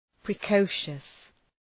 Προφορά
{prı’kəʋʃəs}